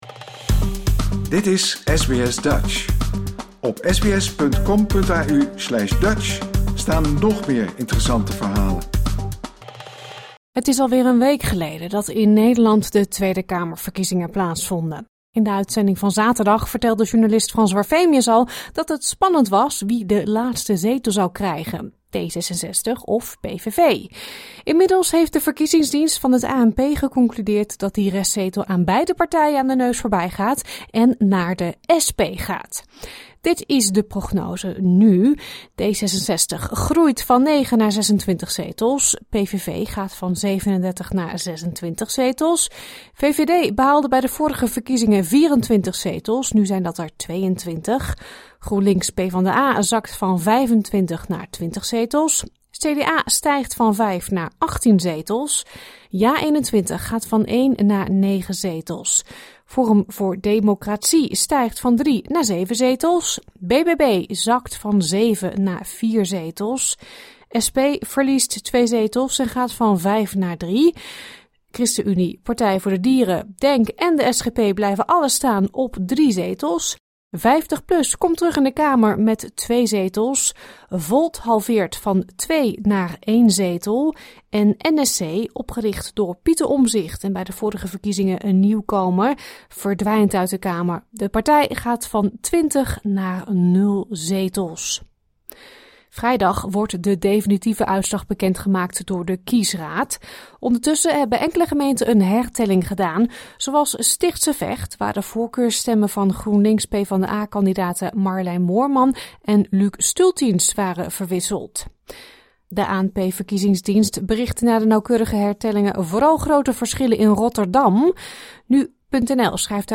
Wie krijgt de restzetel, welke partij is de grootste en hoe hebben Nederlanders in het buitenland gestemd? U hoort het in onze verkiezingsupdate.
Het audiofragment van Jesse Klaver is afkomstig van de NOS. Mis niets van SBS Dutch!